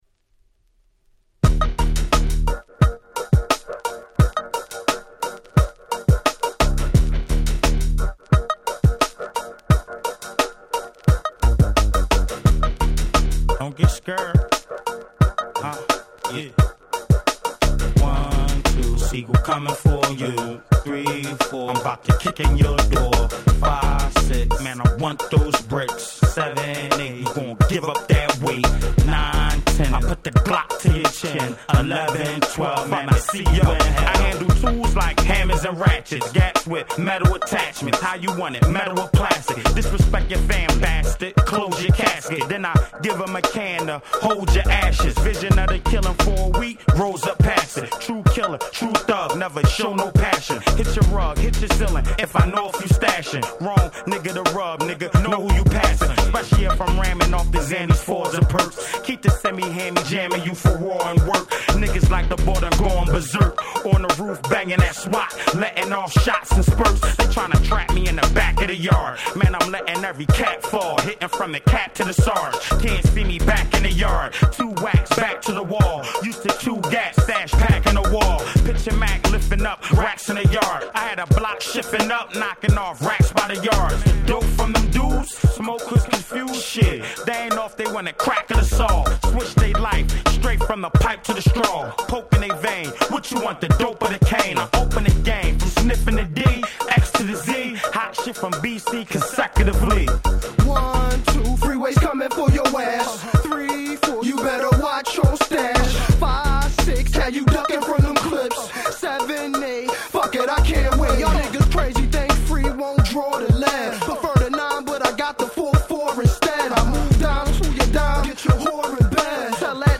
01' Super Hit R&B/Hip Hop !!